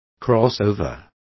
Complete with pronunciation of the translation of crossover.